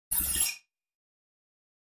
دانلود آهنگ کلیک 52 از افکت صوتی اشیاء
دانلود صدای کلیک 52 از ساعد نیوز با لینک مستقیم و کیفیت بالا
جلوه های صوتی